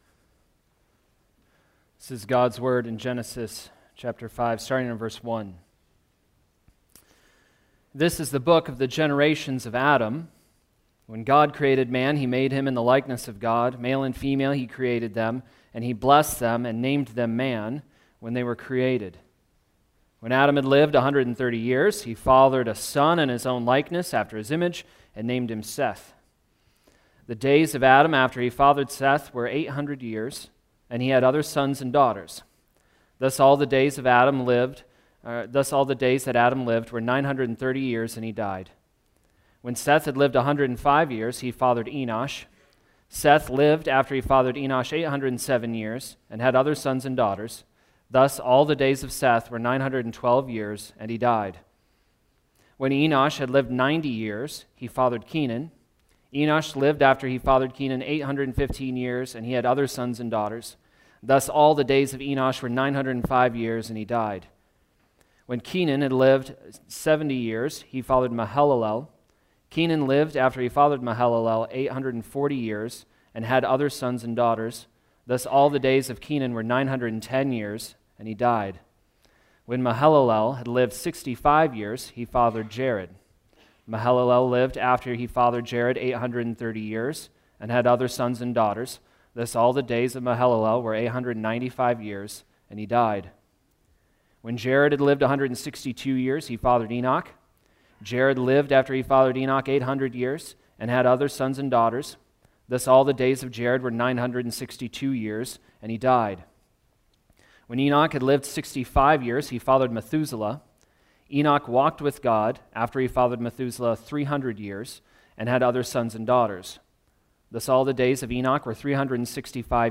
Genesis Sermons